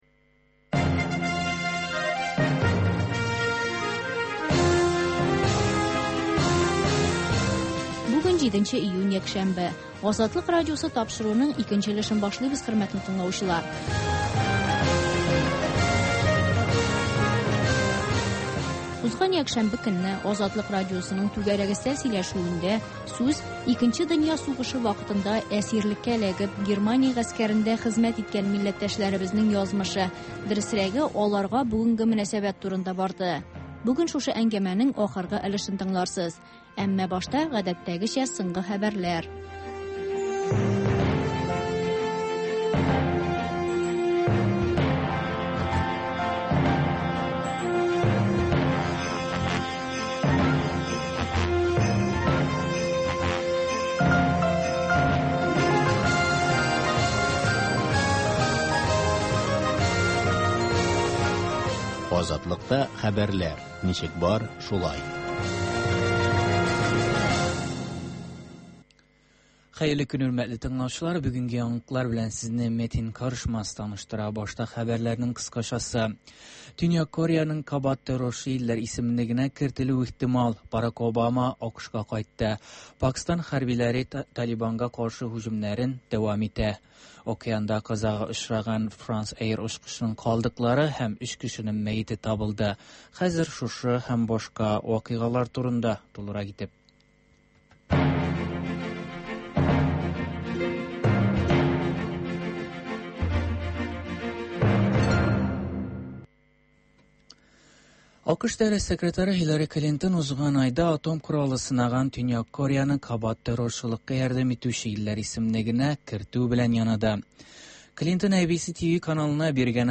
Азатлык узган атнага күз сала - соңгы хәбәрләр - түгәрәк өстәл сөйләшүе